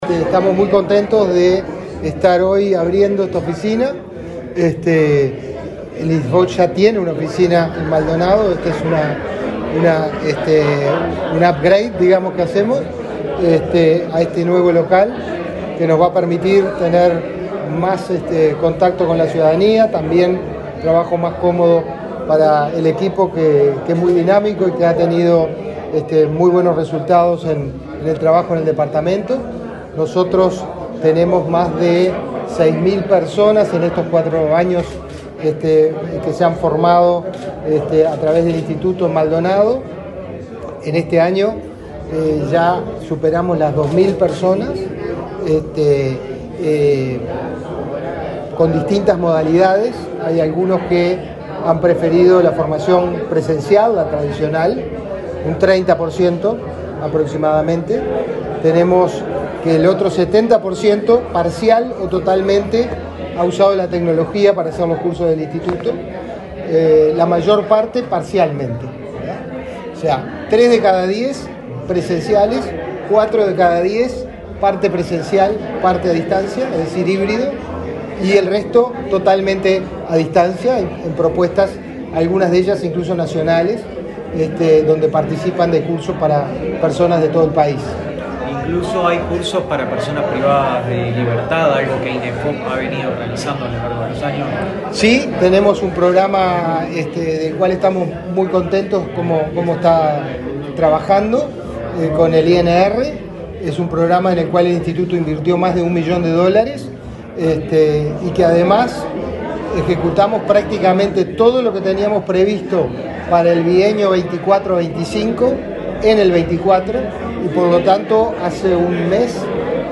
Declaraciones del director del Inefop, Pablo Darscht
Declaraciones del director del Inefop, Pablo Darscht 20/12/2024 Compartir Facebook X Copiar enlace WhatsApp LinkedIn El director del Instituto Nacional de Empleo y Formación Profesional (Inefop), Pablo Darscht, dialogó con la prensa, antes de encabezar el acto de inauguración de la sede del organismo en Maldonado.